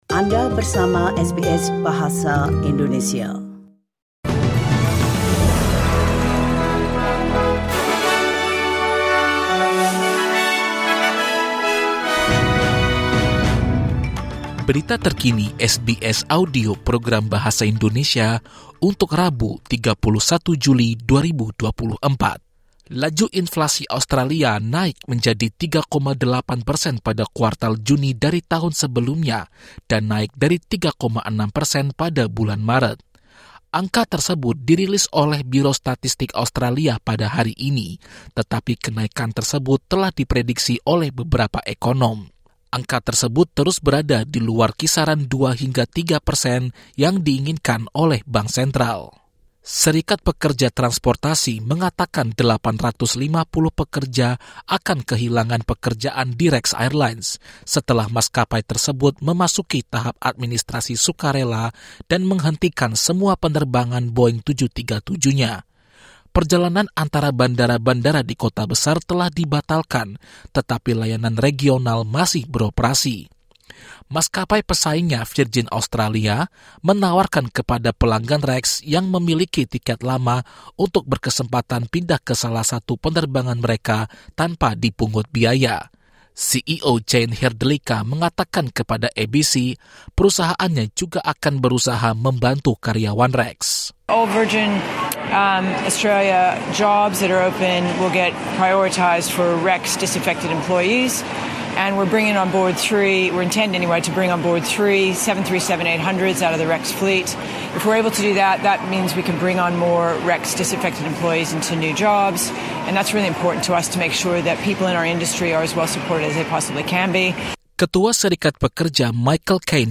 SBS Audio news in Indonesian - 31 July 2024